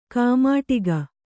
♪ kāmāṭiga